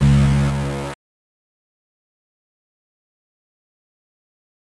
新鲜包装 " Bass05
Tag: 低音 打击乐器 畸变 电子